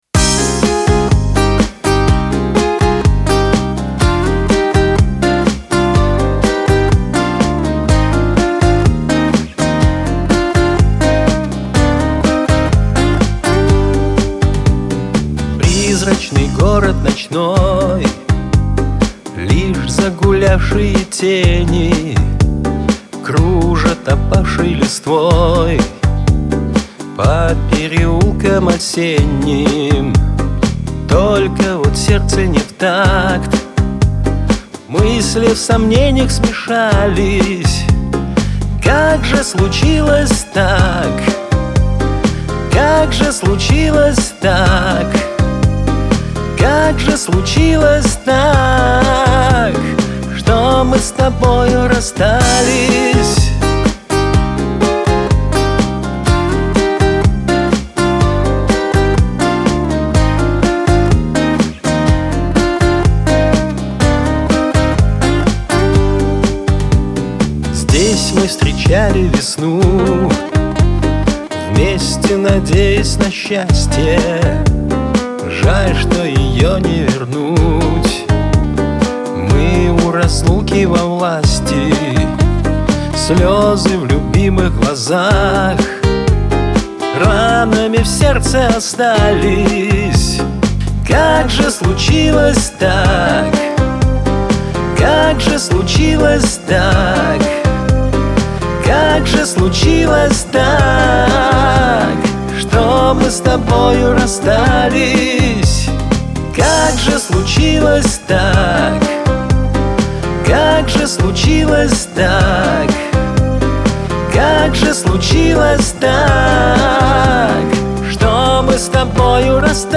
Главная ➣ Жанры ➣ Шансон. 2025.